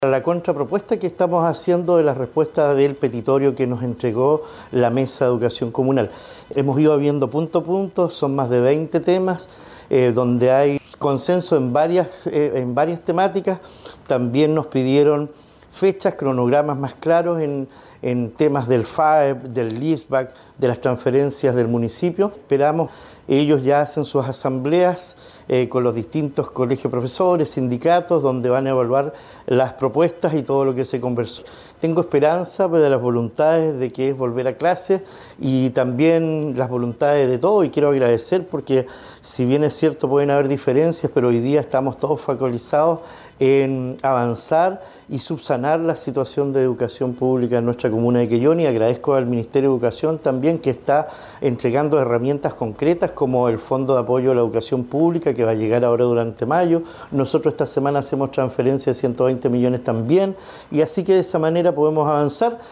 El alcalde de la comuna, Cristian Ojeda, señaló que se pudo conformar un cronograma para la resolución de este paro, señalando que habrá apoyo mediante recursos adelantados del Fondo de Ayuda a la Educación Pública, FAEP, y por transferencias del municipio.